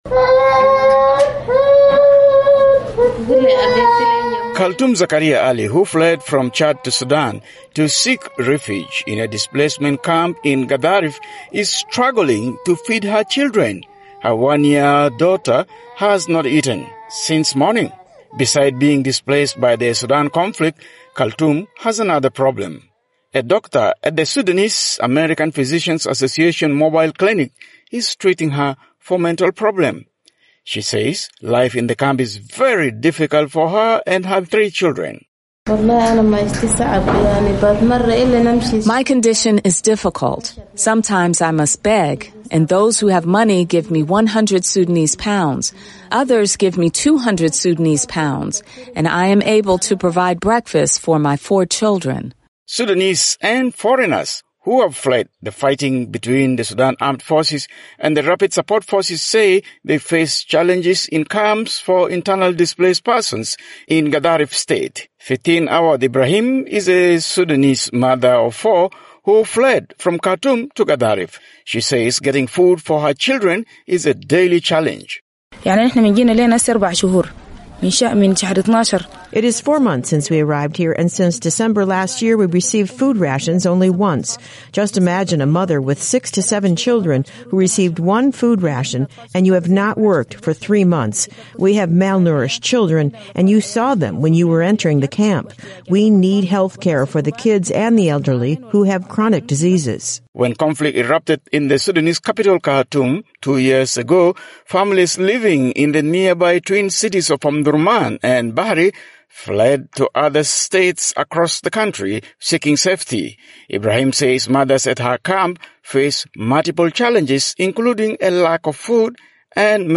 reports from Omdurman